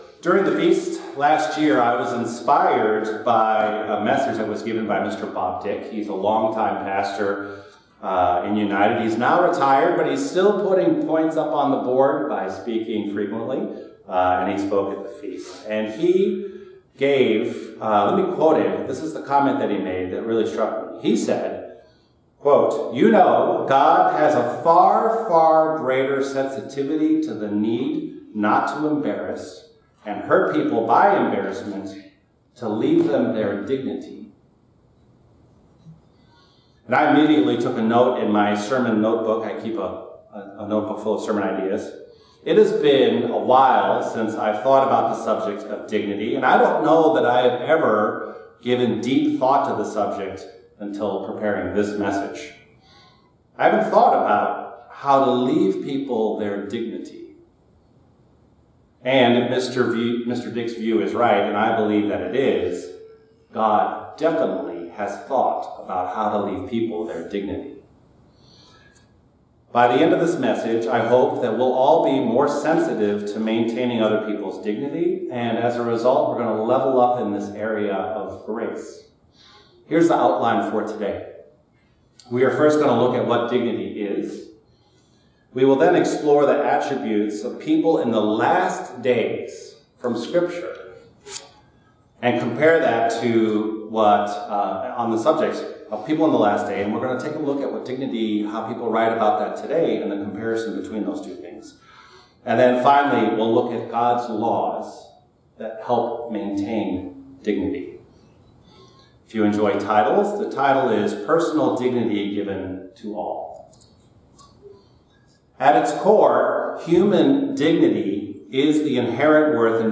Sermon
Given in Northwest Indiana